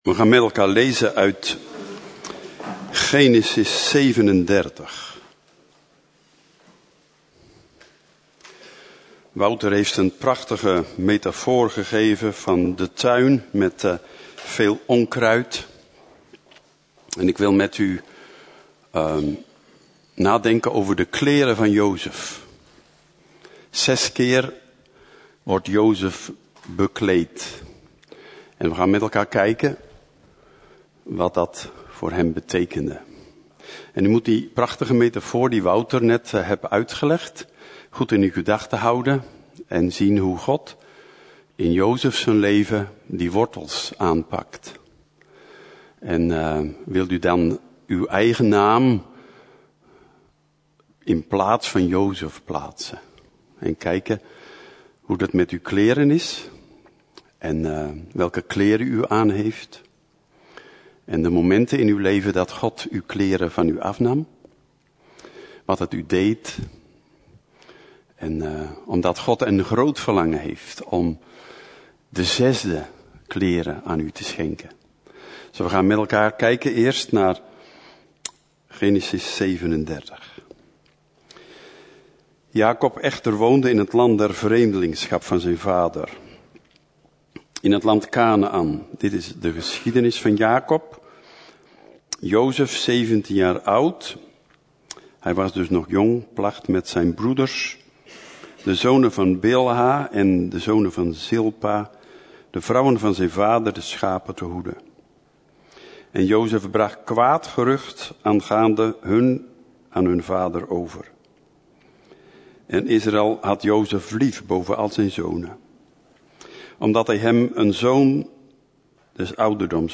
Preek: Jozef - Levende Hoop